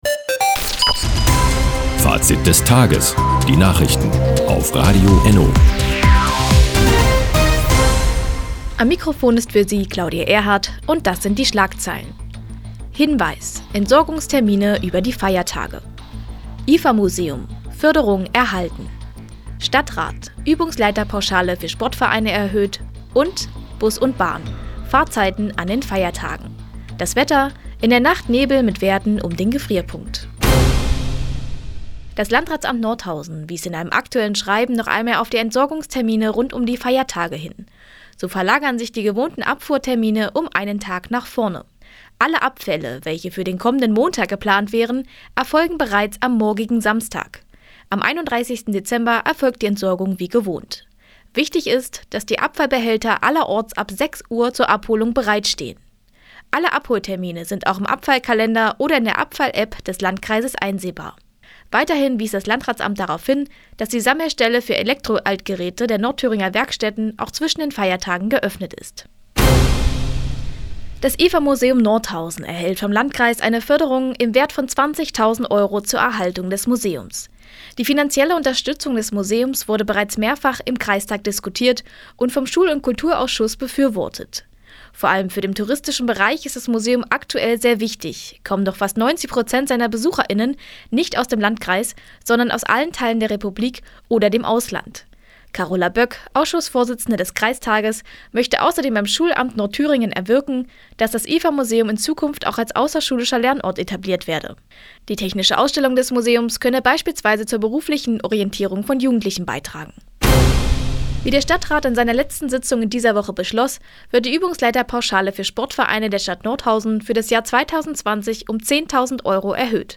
Fr, 16:39 Uhr 18.12.2020 Neues von Radio ENNo Fazit des Tages Anzeige symplr (1) Seit Jahren kooperieren die Nordthüringer Onlinezeitung und das Nordthüringer Bürgerradio ENNO. Die tägliche Nachrichtensendung ist jetzt hier zu hören.